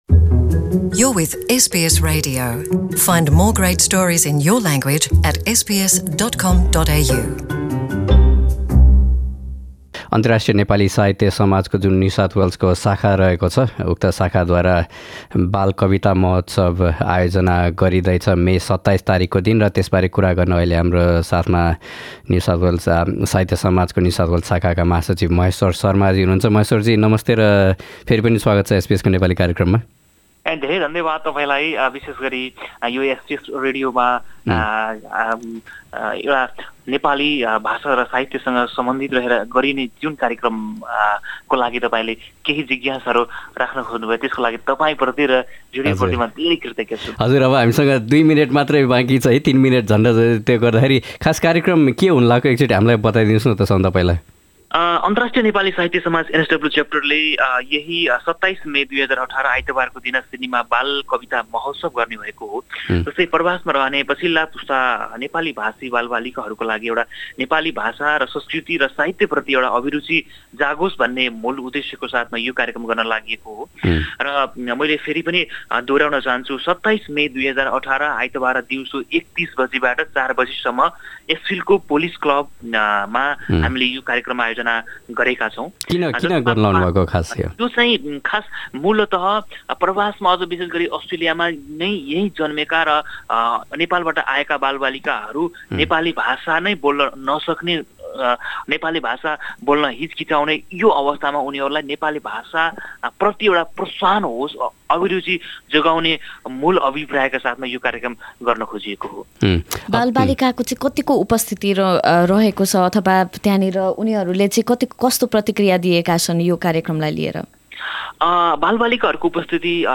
साक्षात्कार सुन्न, कृपया माथिको प्लेयरमा प्ले बटन क्लिक गर्नुहोस्।